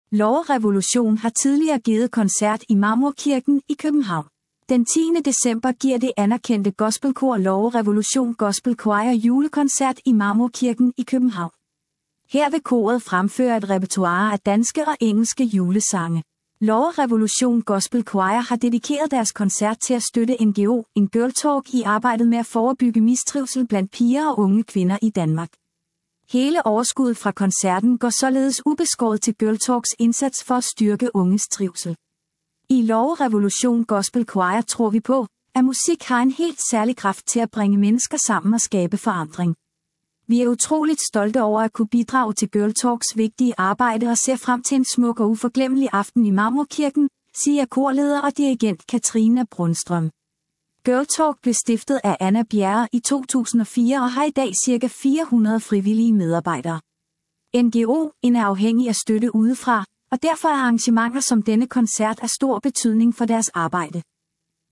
Gospelkoncert i Marmorkirken - Udfordringen
Her vil koret fremføre et repertoire af danske og engelske julesange.